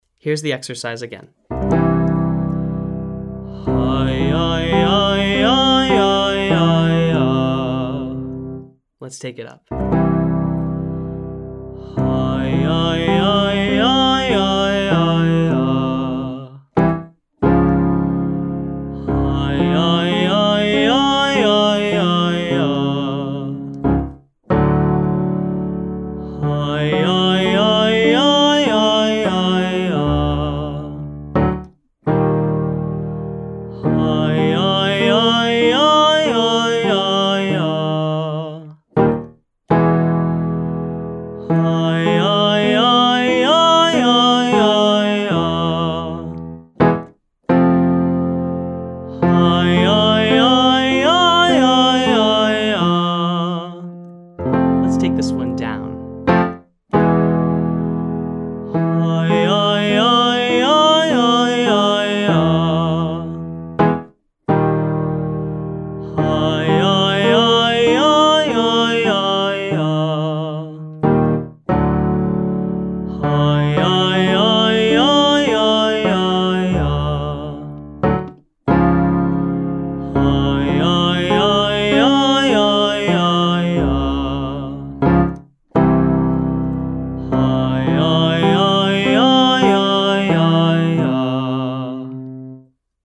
Mix-belt Range Extension - Online Singing Lesson
From: Daily Range Extension Practice For Low Voices
Exercise: Hujahjahjah (1,3,5,8,5,3,1) C to F